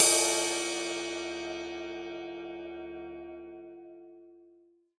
Hats & Cymbals
Cymbal_Ride.wav